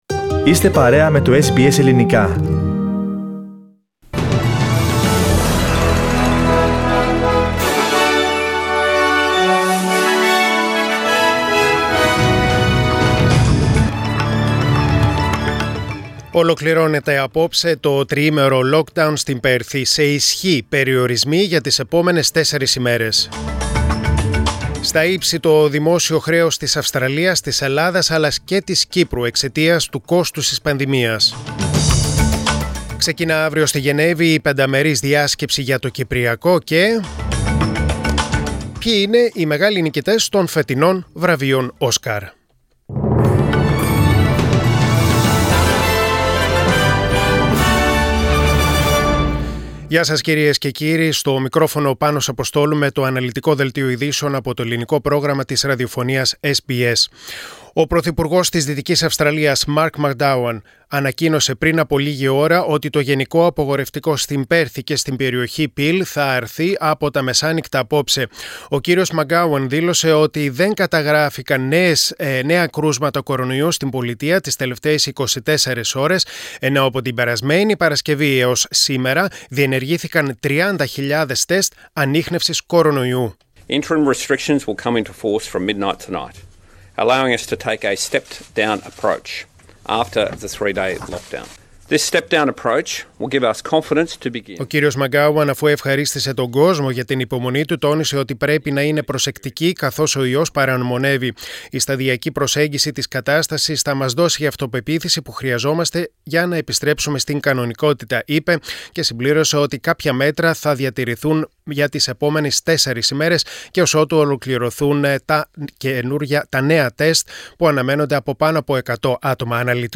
News in Greek: Monday 26.04.2021